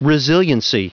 Prononciation du mot resiliency en anglais (fichier audio)
Prononciation du mot : resiliency
resiliency.wav